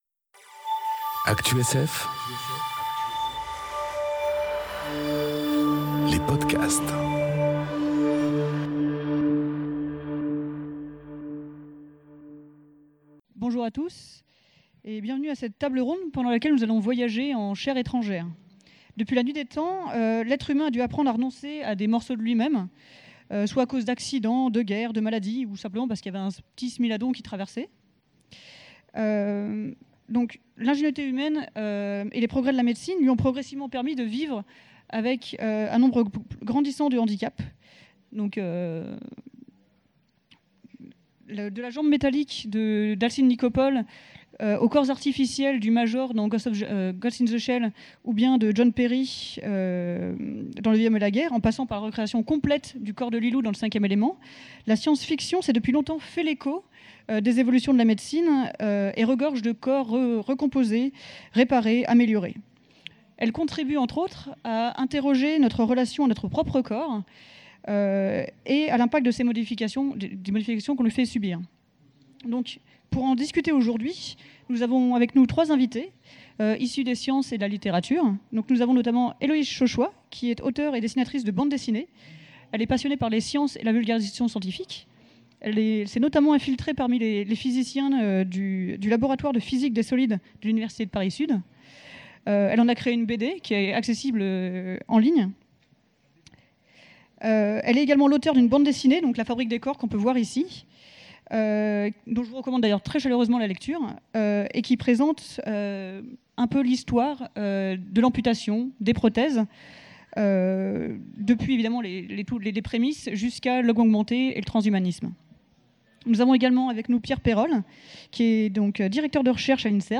Conférence En chair étrangère : accepter un corps étranger enregistrée aux Utopiales 2018